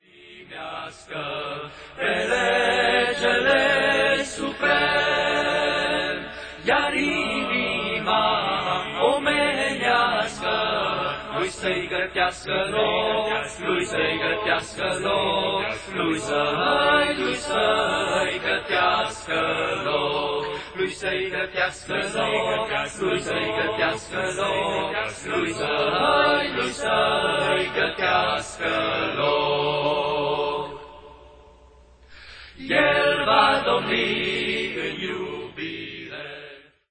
3 voci barbatesti
Pastrand linia melodica traditionala colindelor
colinde de demult. Instrumente precum toba si trompeta